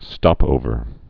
(stŏpōvər)